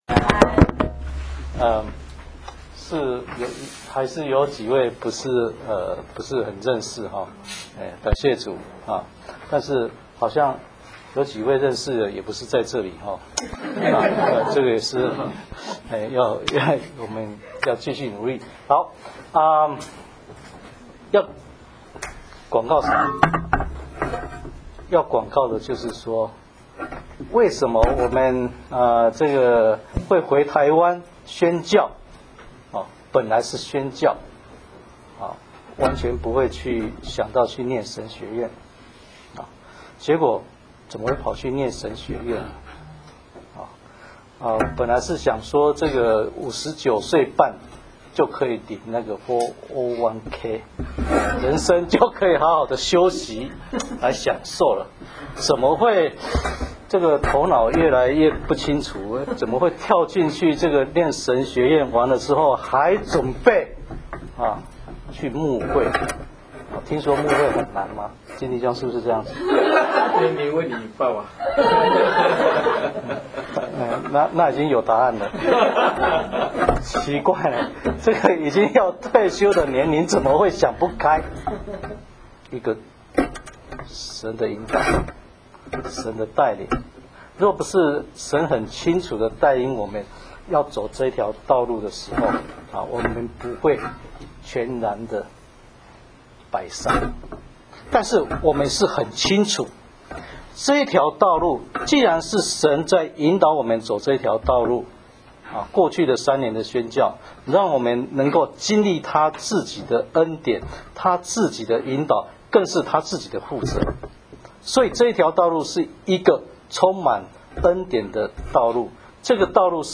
Four Testimony & Sharing.wma